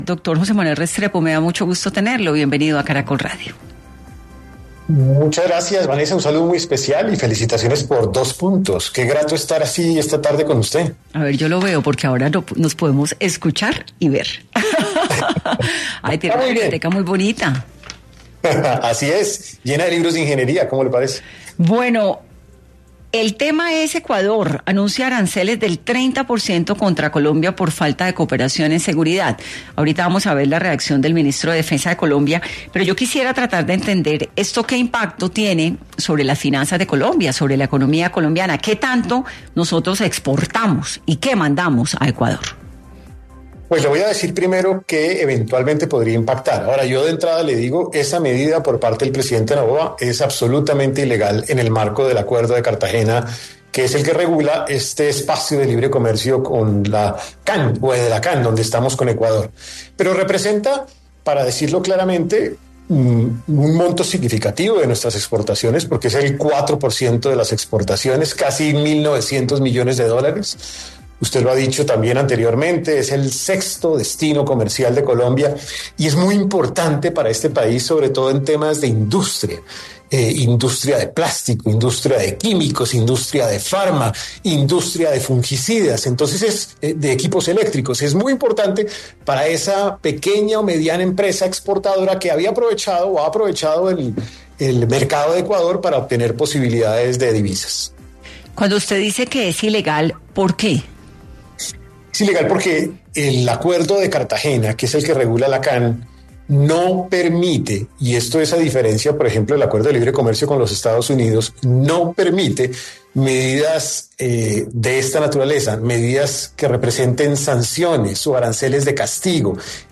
El exministro de Hacienda, José Manuel Restrepo, habló en el programa Dos Puntos, sobre las implicaciones que trae la imposición de aranceles a Colombia por parte de Ecuador.